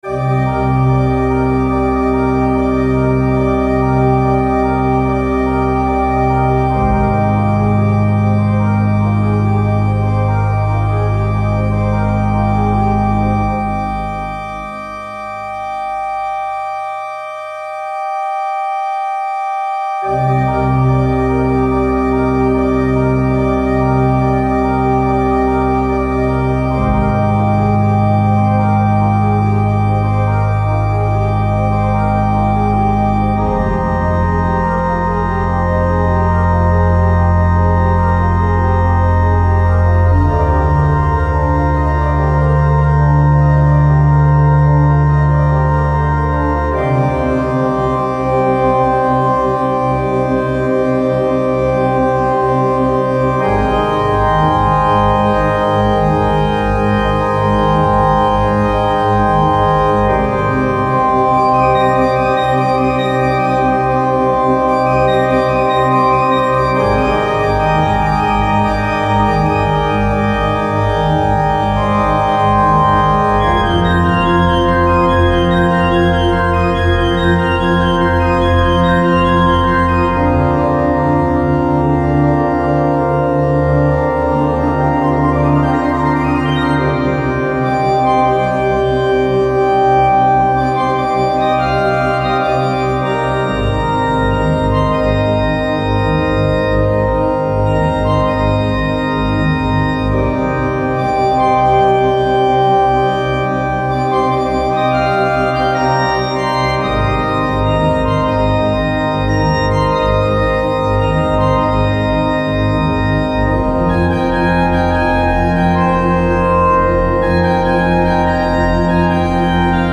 pipe organ